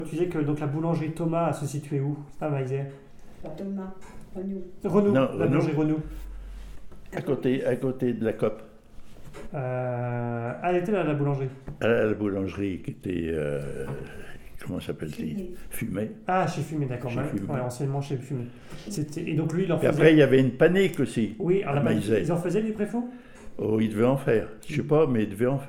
Enquête autour du préfou
Catégorie Témoignage